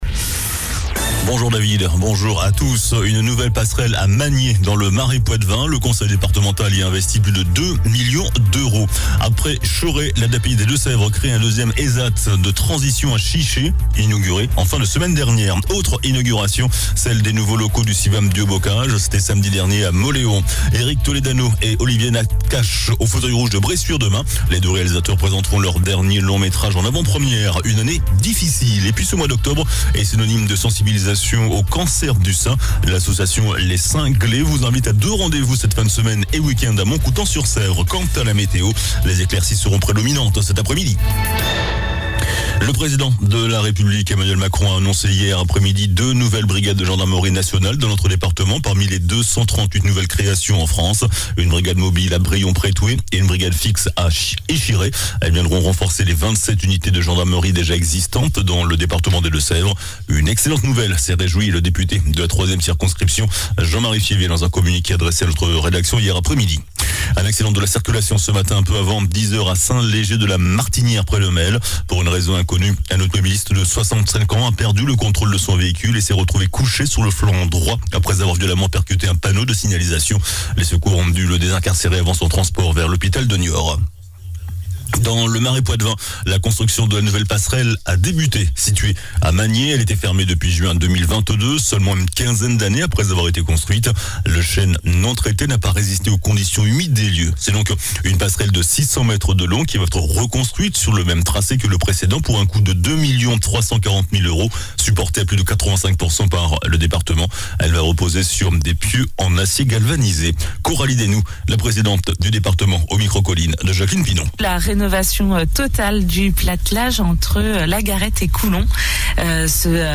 JOURNAL DU MARDI 03 OCTOBRE ( MIDI )